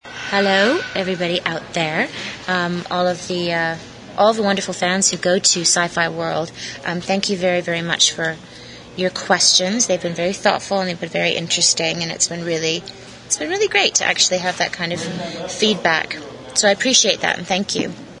rachel_luttrell_message.mp3